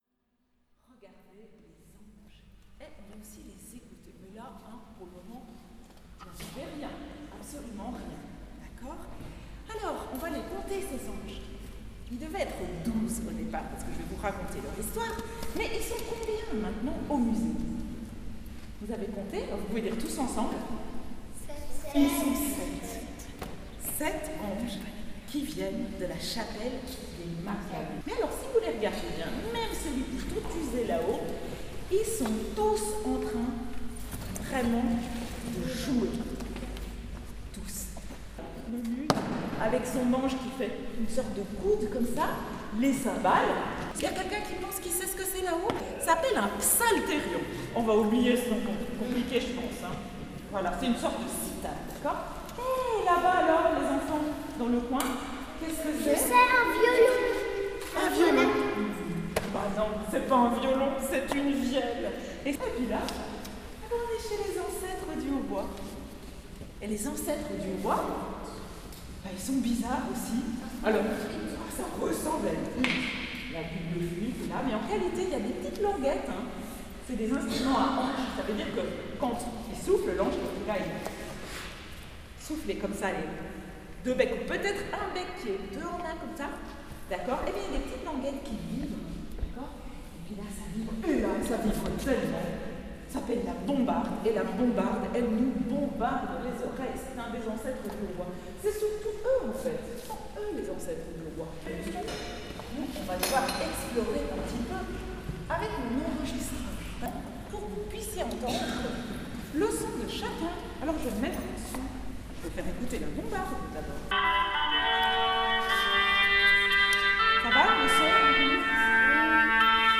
Une visite a particulièrement suscité l’enthousiasme des petits visiteurs : Les anges musiciens. Il s’agissait de faire découvrir au public le son produit par différents instruments anciens joués par 7 anges musiciens exposés au Musée d’Art et d’Histoire.
visite_anges_musiciens_mediation.mp3